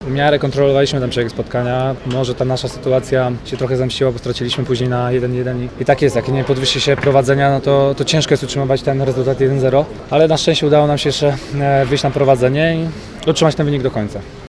– To ważne zwycięstwo na trudnym terenie – mówił po meczu kapitan naszej kadry Robert Lewandowski.